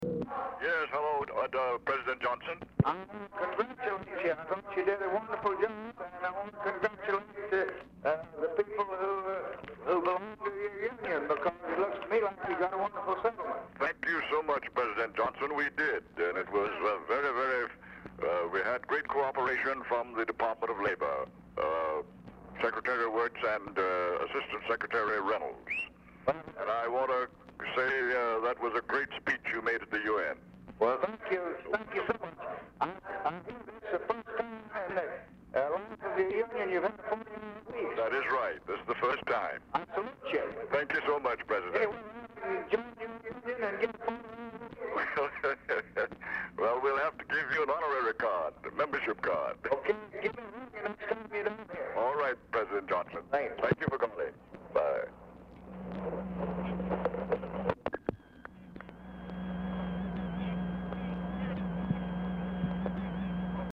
Telephone conversation # 554, sound recording, LBJ and A. PHILIP RANDOLPH, 12/18/1963, 6:50PM | Discover LBJ
POOR SOUND QUALITY
Format Dictation belt
Location Of Speaker 1 Oval Office or unknown location
Specific Item Type Telephone conversation